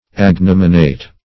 \Ag*nom"i*nate\ ([a^]g*n[o^]m"[i^]*n[=a]t)